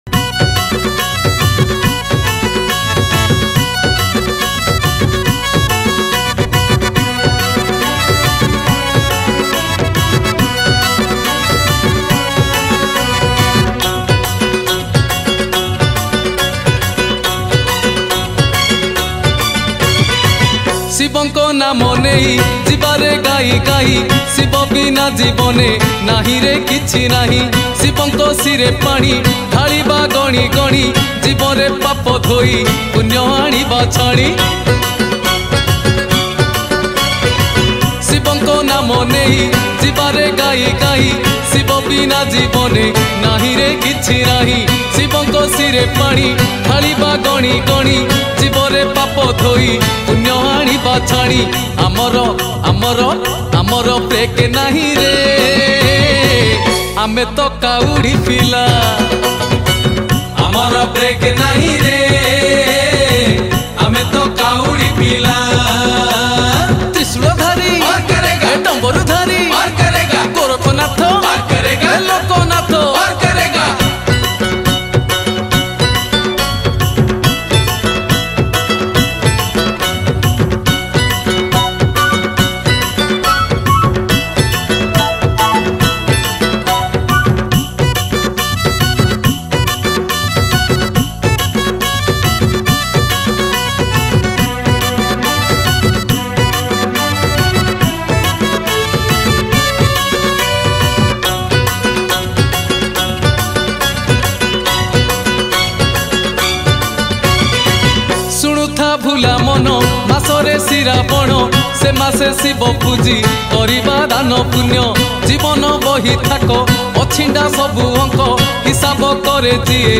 Bolbum Special Song